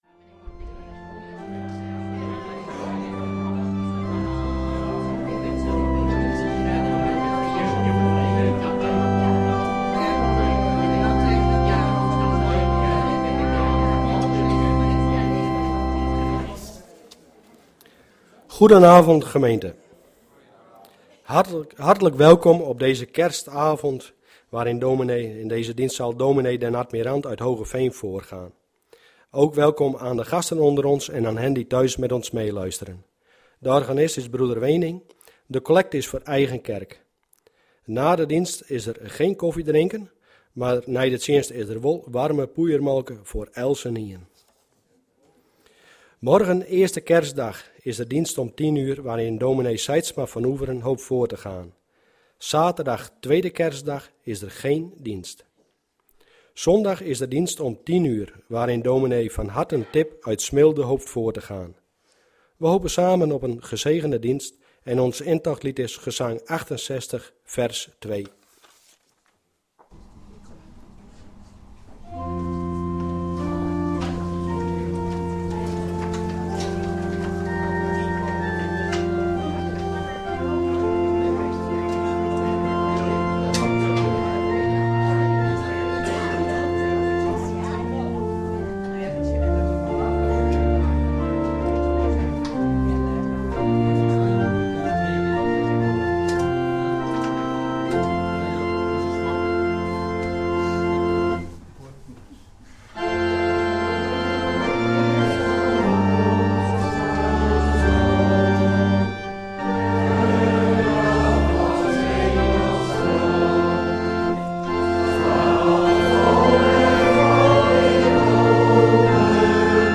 Vereiste velden zijn gemarkeerd met * Reactie * Naam * E-mail * Site ← Newer Preek Older Preek →